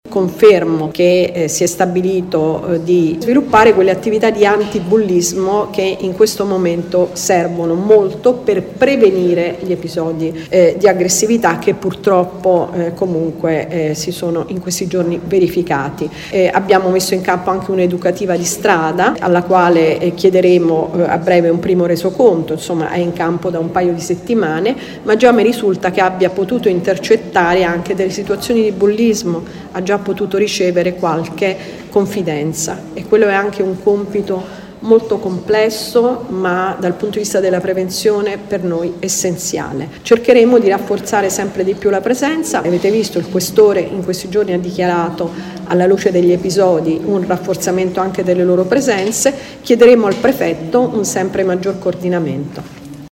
Qui sotto le parole dell’assessore Camporota